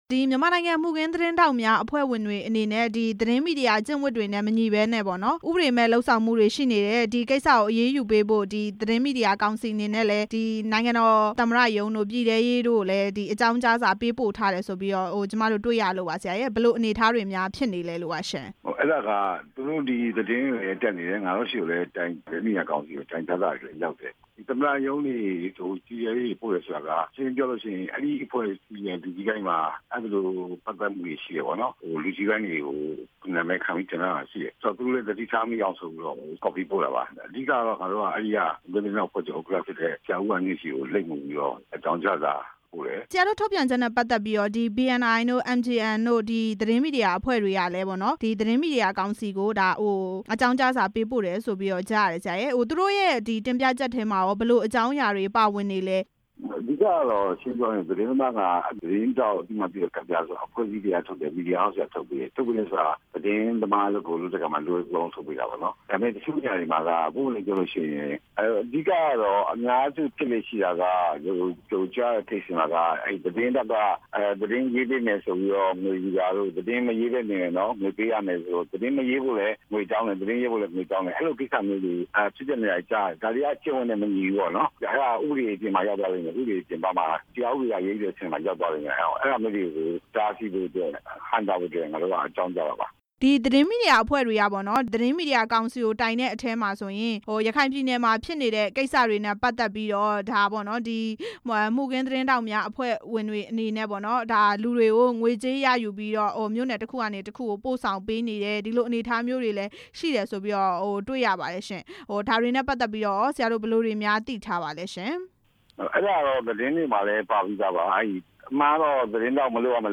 မှုခင်းသတင်းထောက် တိုင်းကြားခံရမှု မေးမြန်းချက်